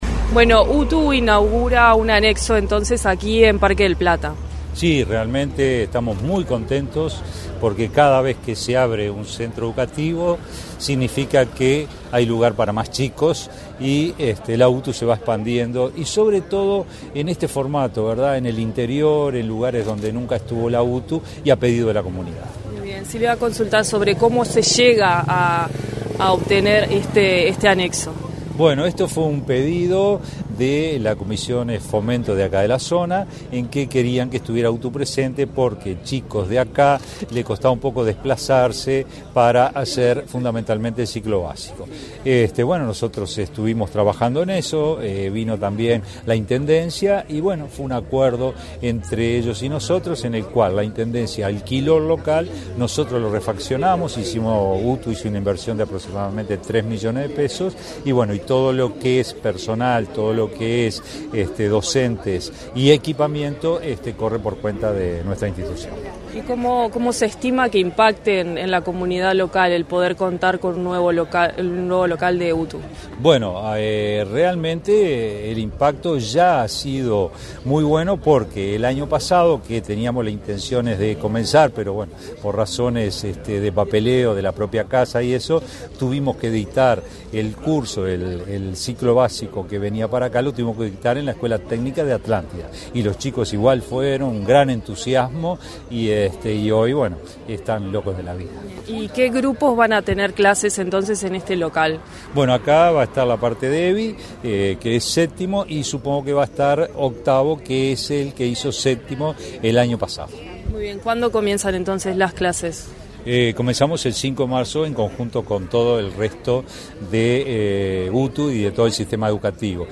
Entrevista al director general de UTU, Juan Pereyra
Entrevista al director general de UTU, Juan Pereyra 20/02/2025 Compartir Facebook X Copiar enlace WhatsApp LinkedIn Este 20 de febrero, la Dirección General de Educación Técnico Profesional (DGETP-UTU) inauguró en Parque del Plata un anexo de la escuela técnica de Atlántida. En la oportunidad, el titular de la DGETP-UTU, Juan Pereyra, realizó declaraciones a Comunicación Presidencial.